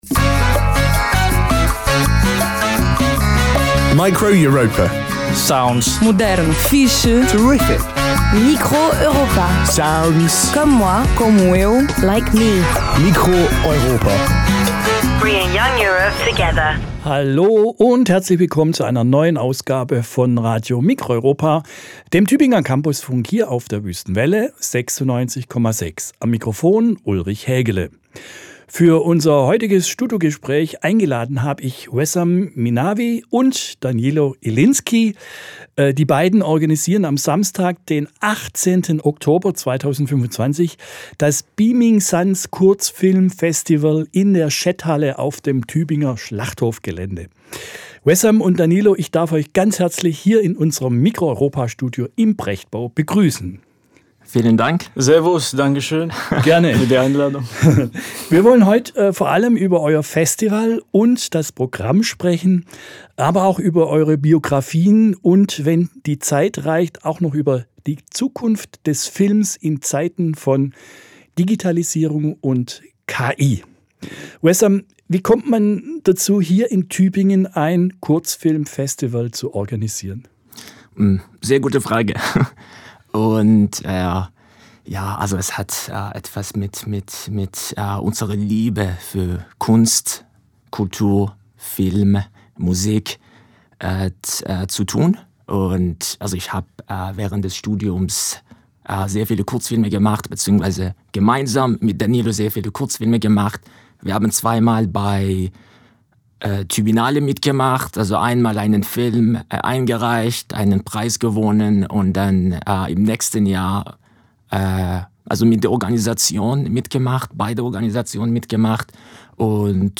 Beamin' Suns Filmfestival: Studiogespräch
Form: Live-Aufzeichnung, geschnitten